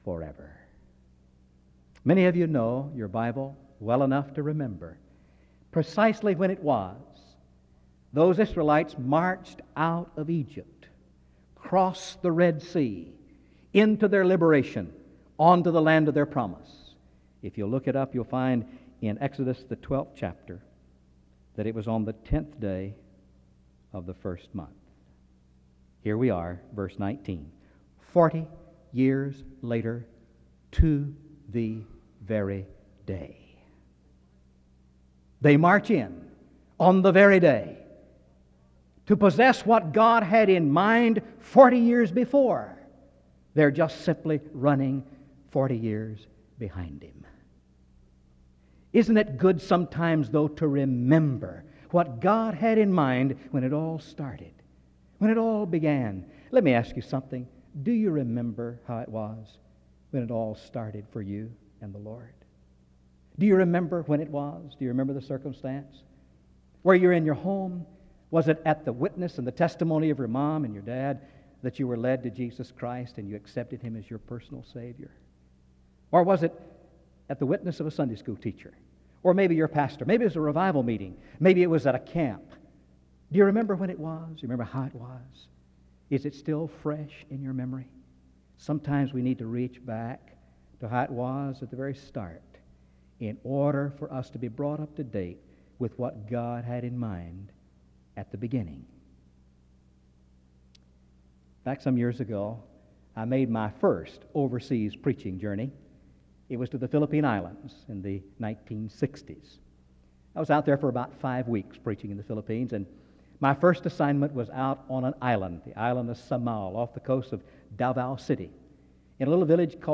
Download .mp3 Description "A Time of Refreshing" The audio was transferred from audio cassette.